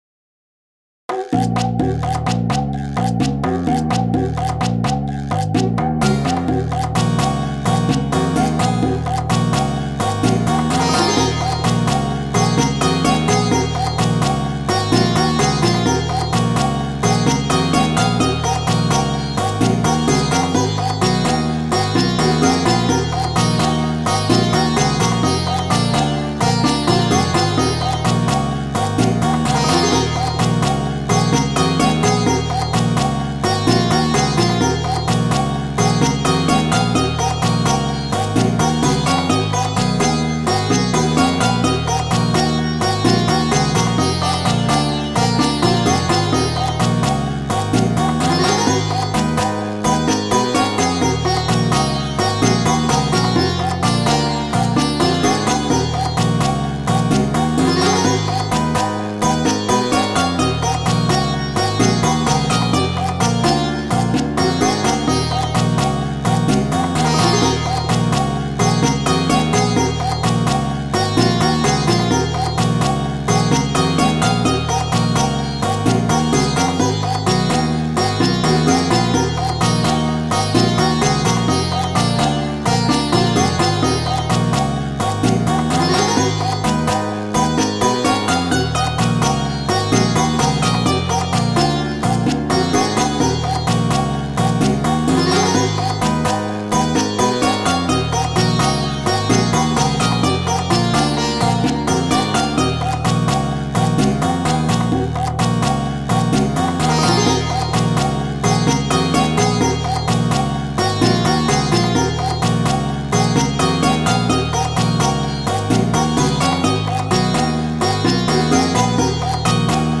ロング暗い民族